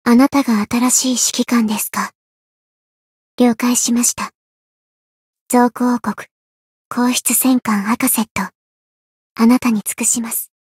灵魂潮汐-阿卡赛特-人偶初识语音.ogg